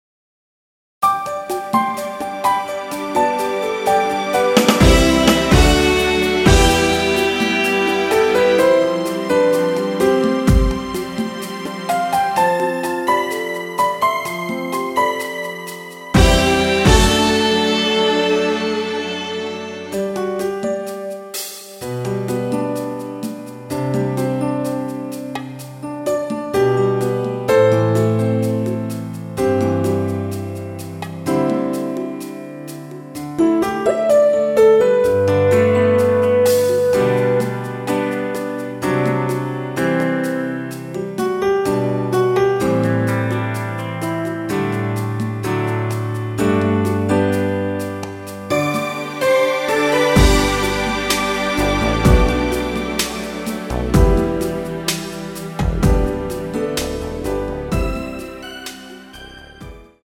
◈ 곡명 옆 (-1)은 반음 내림, (+1)은 반음 올림 입니다.
앞부분30초, 뒷부분30초씩 편집해서 올려 드리고 있습니다.
중간에 음이 끈어지고 다시 나오는 이유는
축가 MR